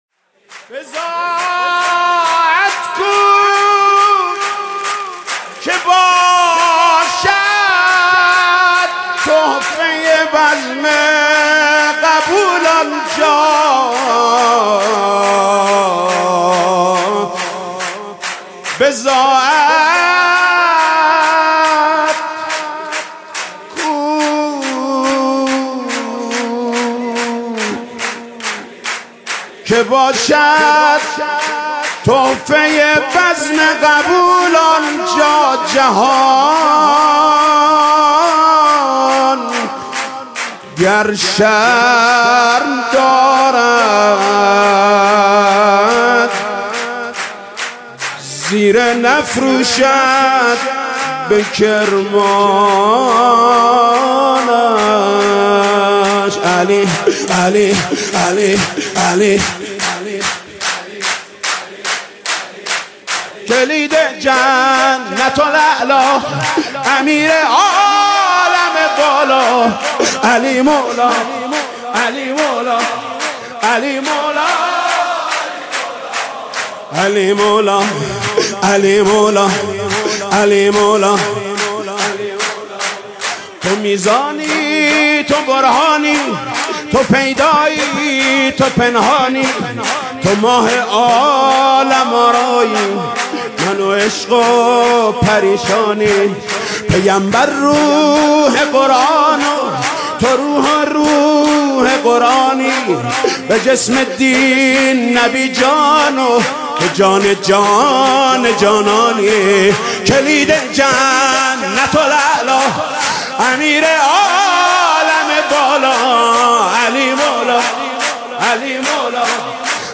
مداحی زیبا و دلنشین